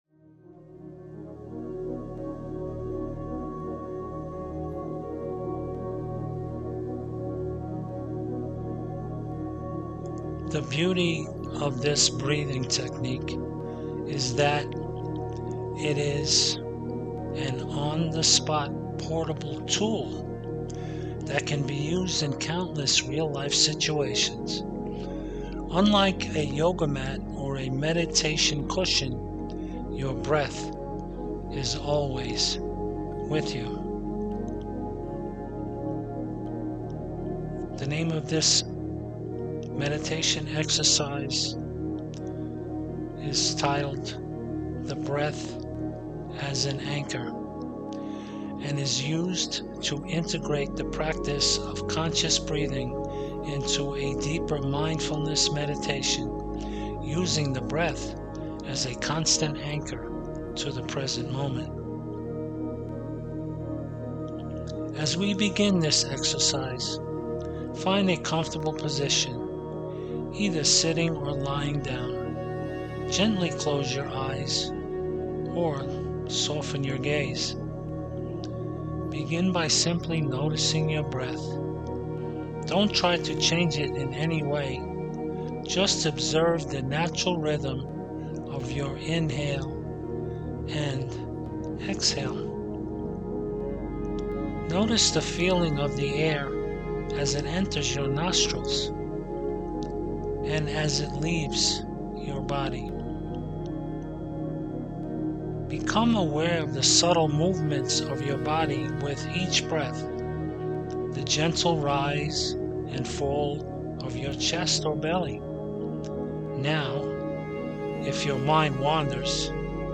Anchor-The-Breath-Guided-Meditation.mp3